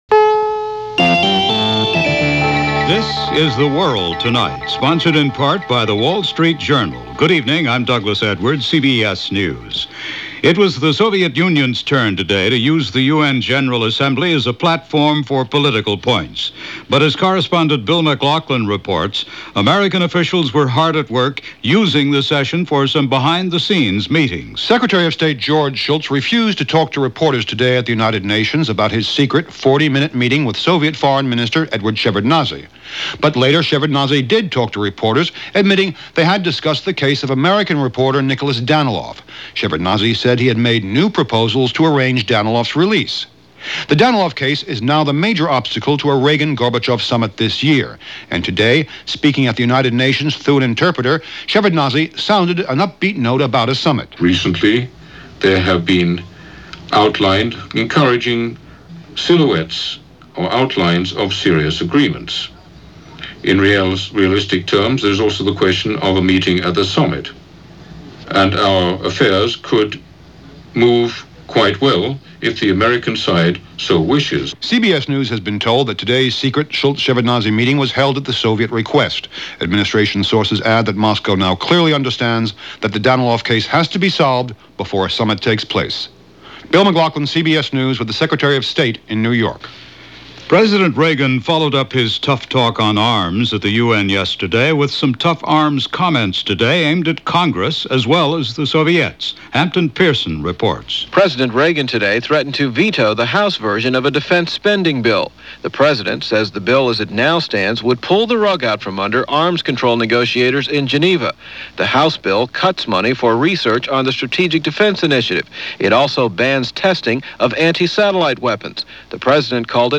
CBS Radio: The World Tonight